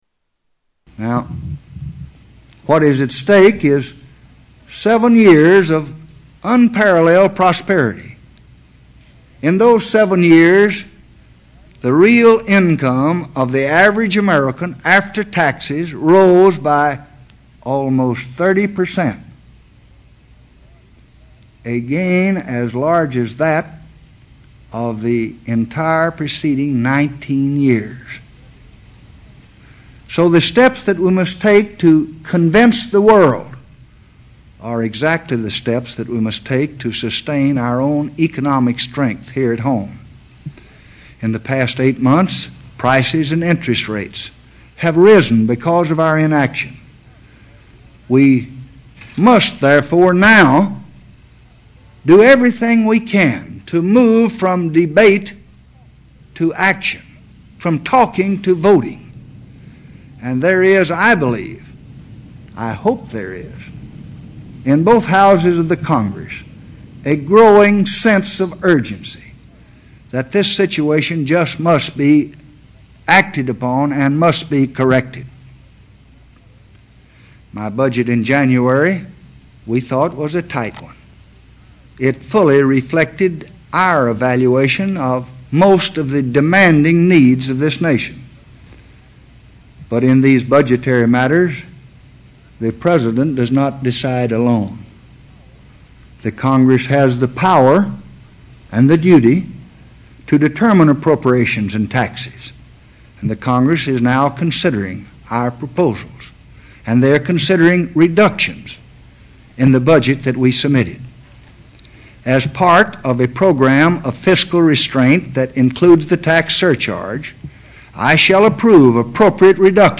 Renunciation Speech Part 4